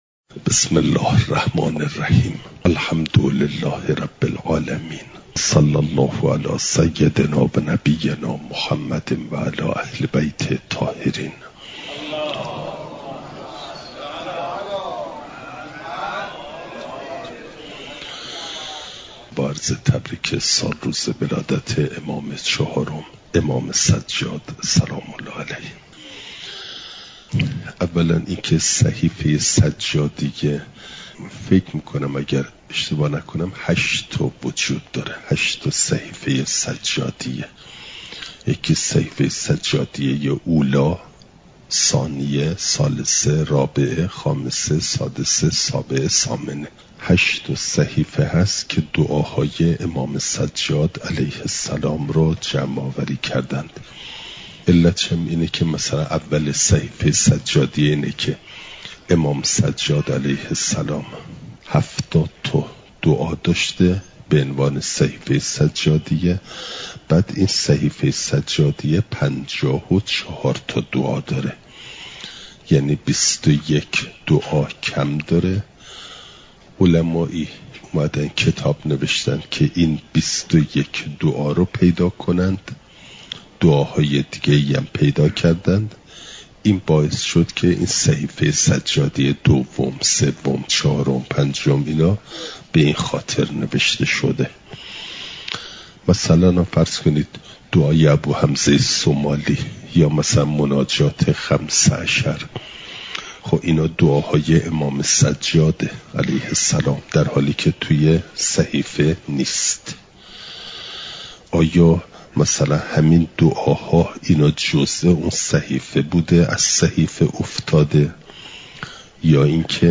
یکشنبه ۵ بهمن‌ماه ۱۴۰۴، حرم مطهر حضرت معصومه سلام‌ﷲ‌علیها